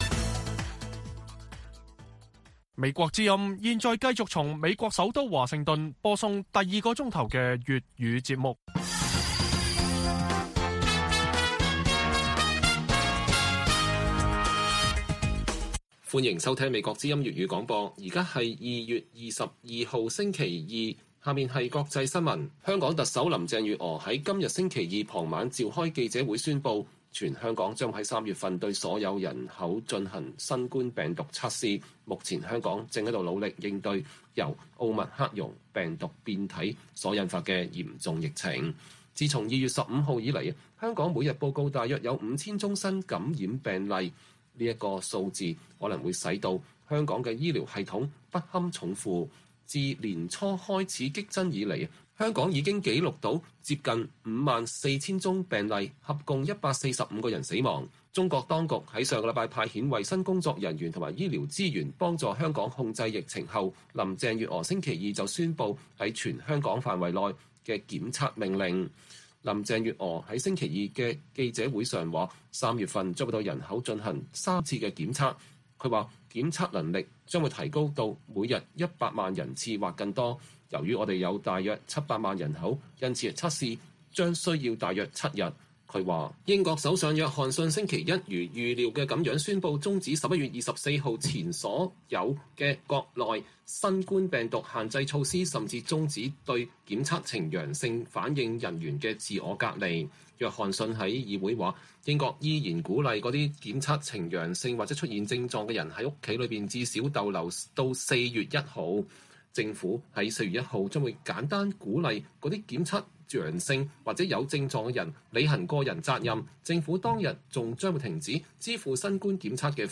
粵語新聞 晚上10-11點： 香港特首公佈實行全民強制檢測 學者憂引發社會不滿